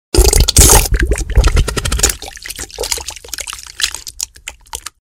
Diarrhea Fart Sound Meme sound effects free download
Diarrhea Fart Sound - Meme Sound Effect